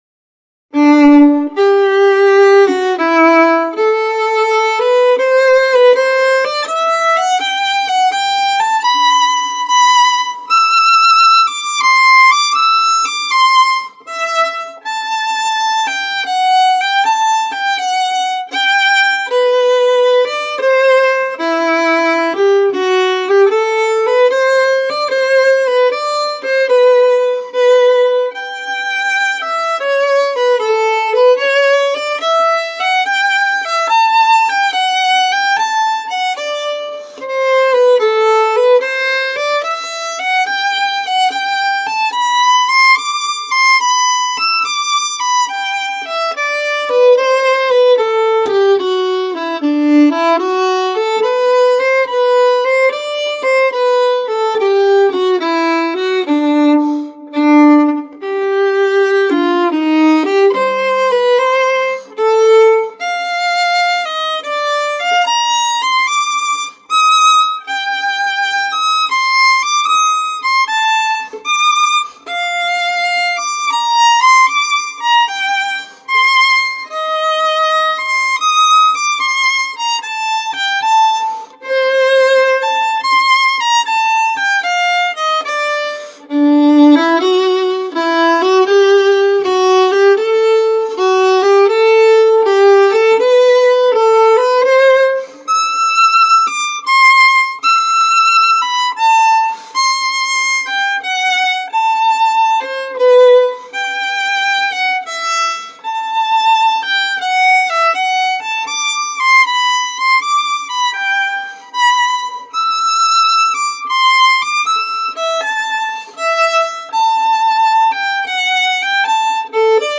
Un poco aburridos y feos, pero dan resultado..